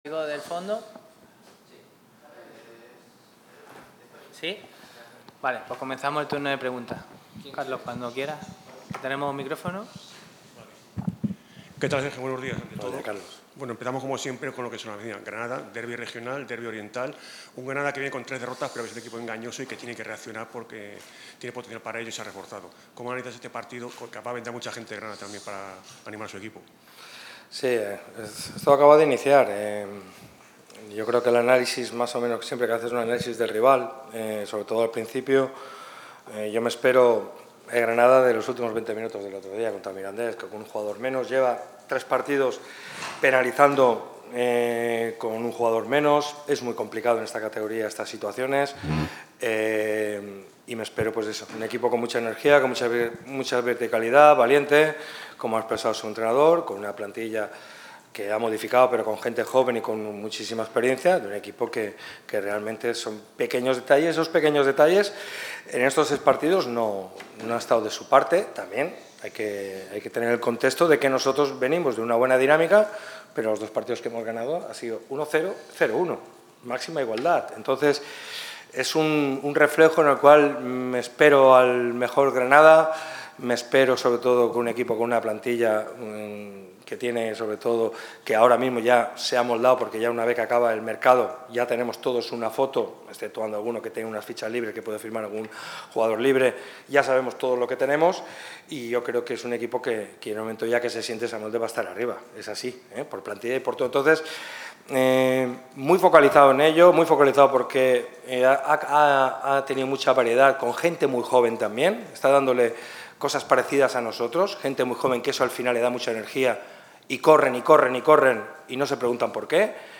Así lo ha explicado Sergio Pellicer en sala de prensa.
El técnico malaguista ha comparecido ante los medios de comunicación en la previa del duelo que enfrentará al Málaga CF ante el Granada CF. No lo hace en un día cualquiera, sino que se da el día posterior a la rueda de prensa de balance de mercado de Loren Juarros. Por ende, el técnico malaguista habla sobre esa valoración del director deportivo, analiza el fichaje de Darko Brasanac y, por supuesto, analiza al conjunto de Pacheta.